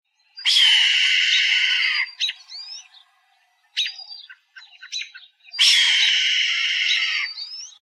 Buteo jamaicensis
Hawk, Red-tailed
Hawk_Red-tailed.oga